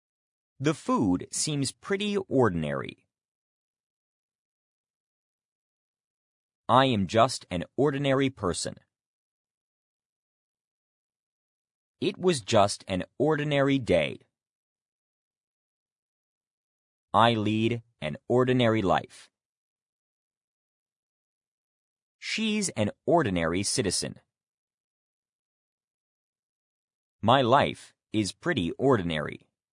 ordinary-pause.mp3